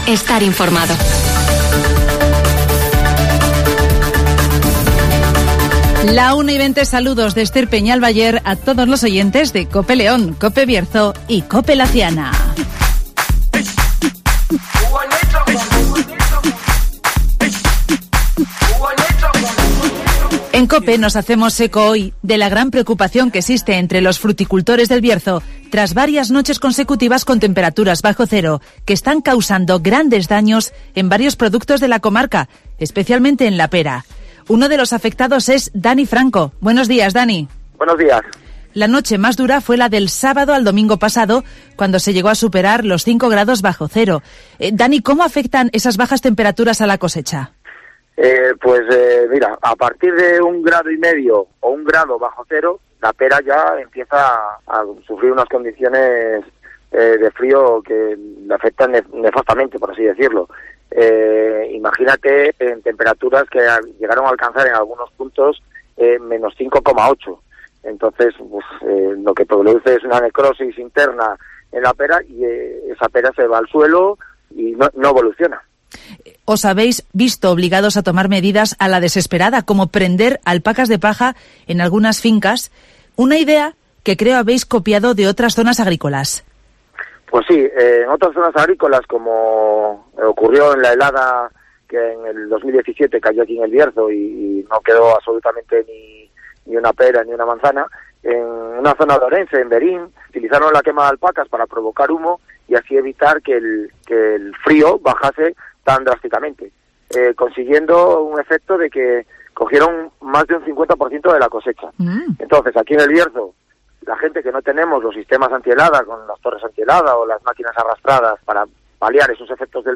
Gran preocupación en el campo berciano por las heladas de la última semana (Entrevista